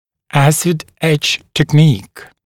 [‘æsɪd-eʧ tek’niːk][‘эсид-эч тэк’ни:к]техника приклеивания с использованием кислотного травления, техника кислотного траления